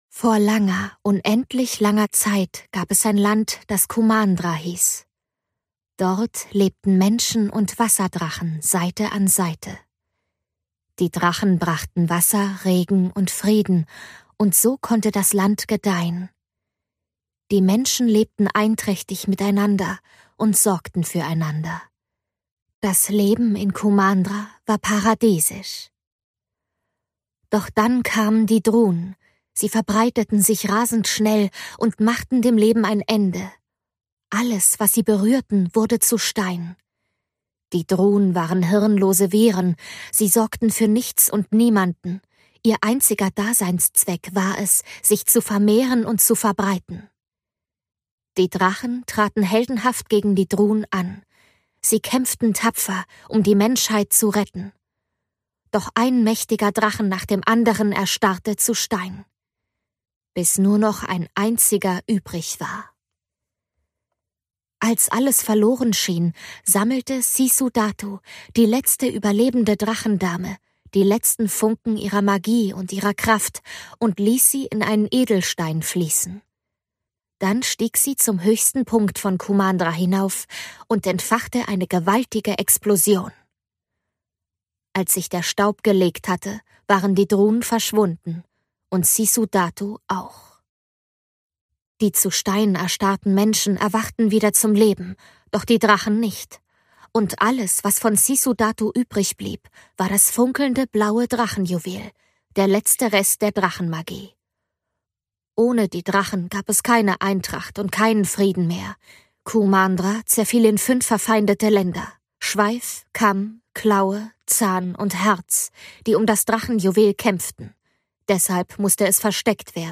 Hörbuch: Raya und der letzte Drache